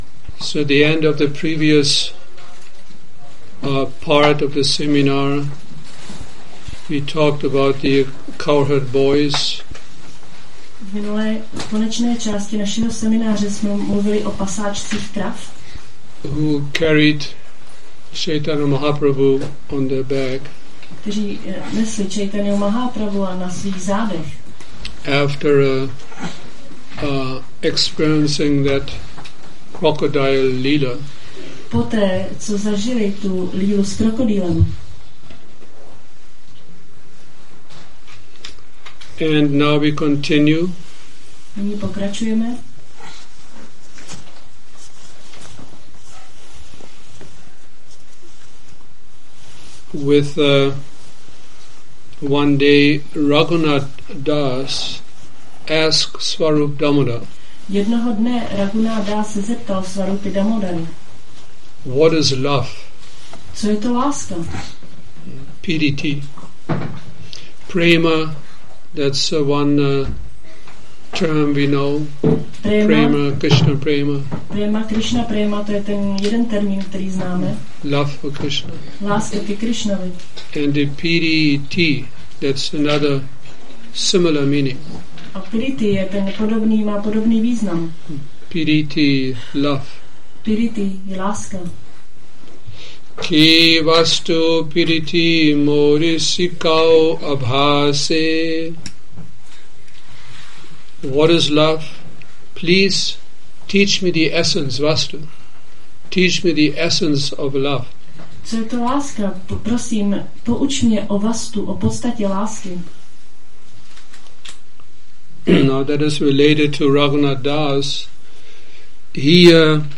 Šrí Šrí Nitái Navadvípačandra mandir
Seminář Prema Vivarta 06